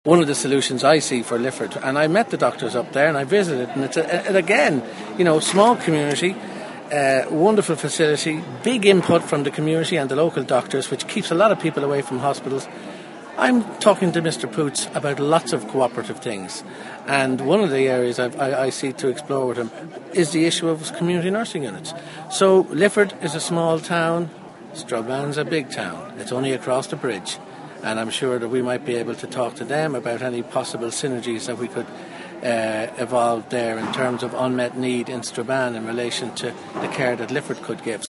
Dr James Reilly says negotiations with health authorities in the north are ongoing: